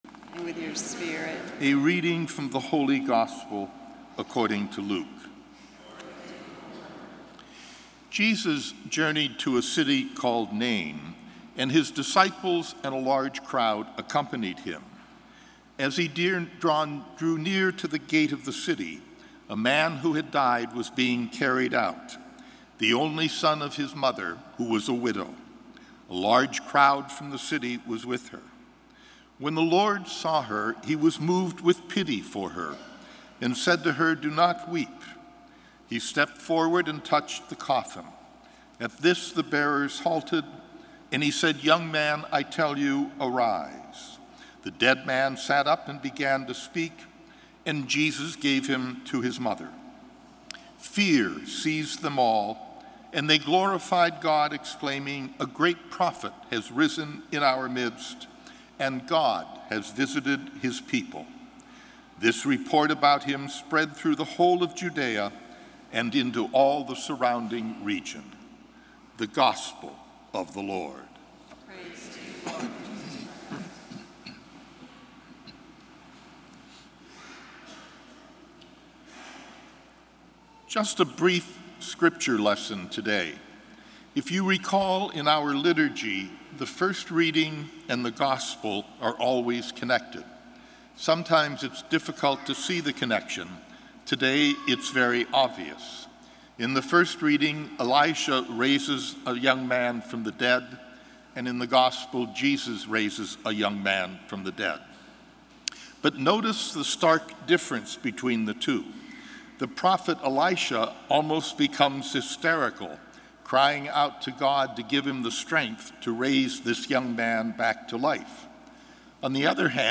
Gospel & Homily June 5, 2016